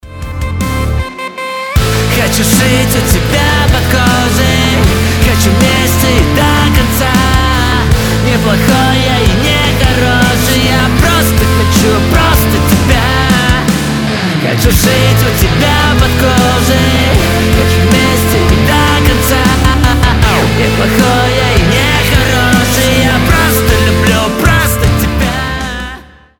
громкие
Pop Rock